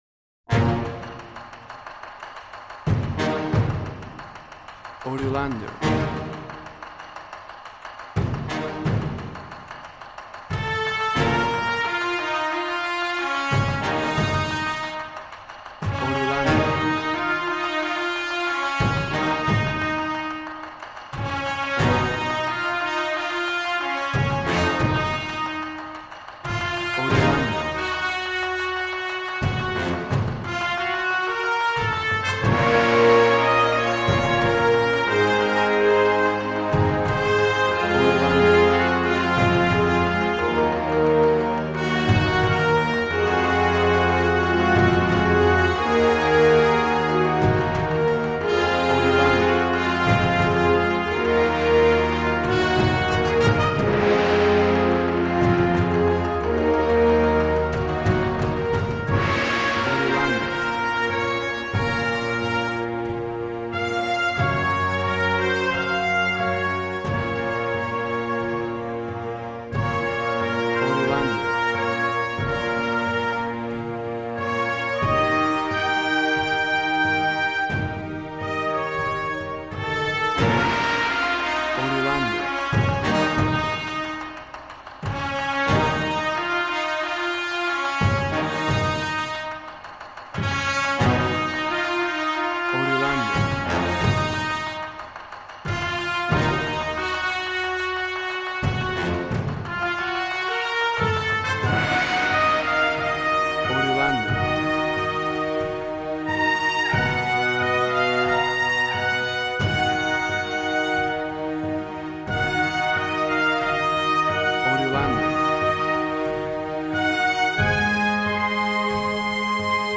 Action and Fantasy music for an epic dramatic world!
Tempo (BPM): 91